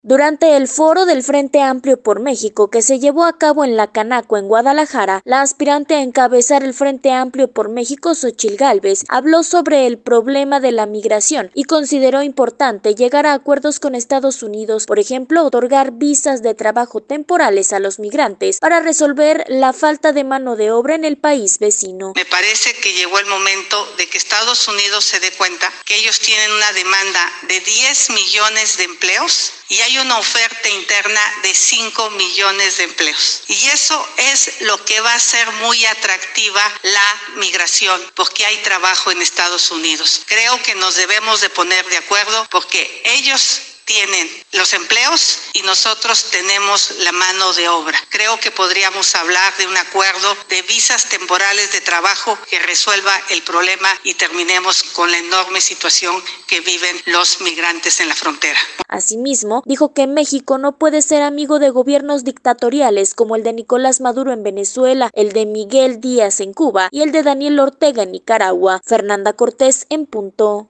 Durante el Foro del Frente Amplio por México, realizado en la Cámara de Comercio en Guadalajara, la aspirante a encabezar esta coalición, Xochil Gálvez habló sobre el problema de la migración y consideró importante y urgente llegar a acuerdos con Estados Unidos, por ejemplo otorgar visas de trabajo temporales a los migrantes para resolver la falta de mano de obra en el país vecino.